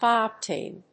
アクセントhígh‐óctane